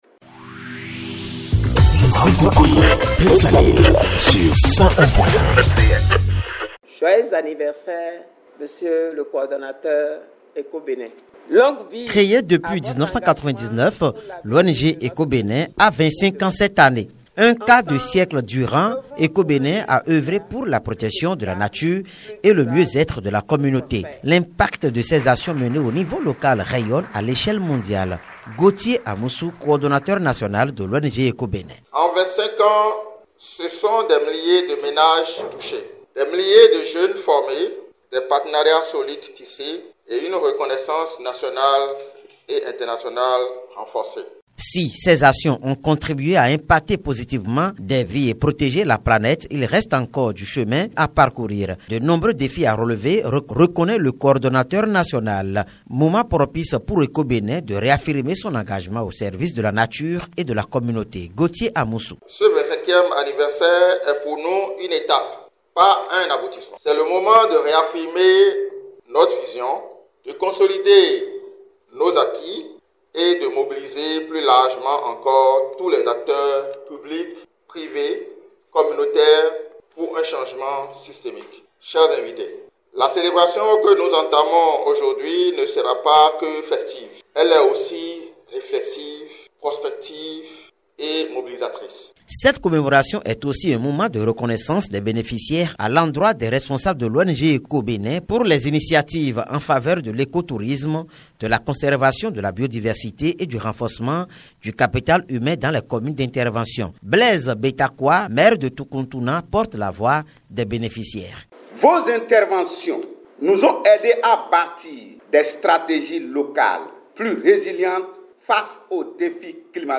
L’ONG Eco-Bénin célèbre ses 25 ans d’existence et d’impacts au service de l’écotourisme, de l’environnement et du développement durable au Bénin. La cérémonie officielle marquant cette célébration a eu lieu le mercredi 24 septembre 2025 à Azalaï Hôtel à Cotonou.
REPORTAGE-CELEBRATION-25-ANS-ECO-BENIN.mp3